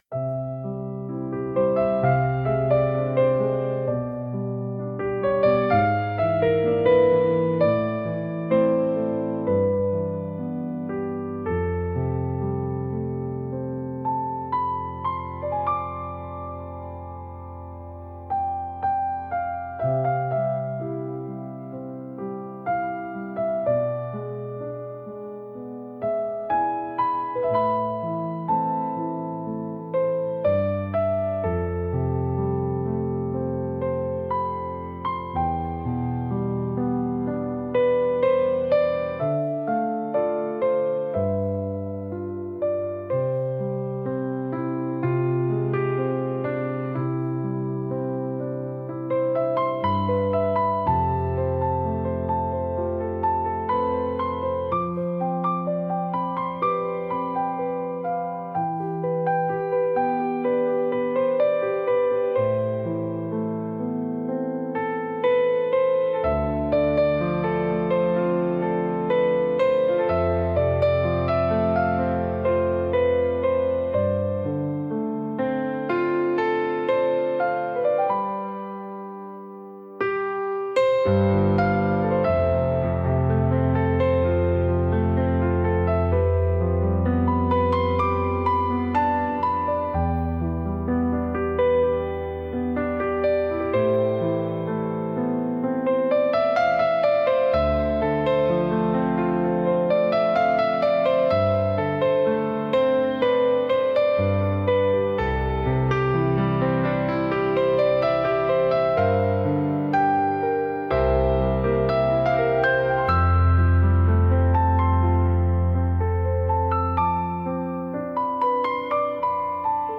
繊細で情感豊かな空気を醸し出すジャンルです。